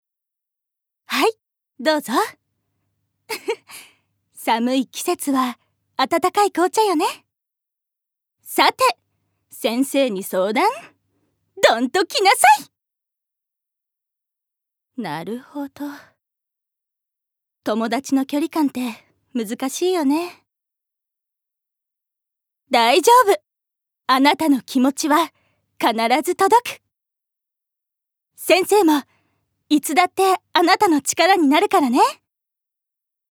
ボイスサンプル
セリフ１